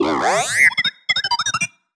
sci-fi_driod_robot_emote_17.wav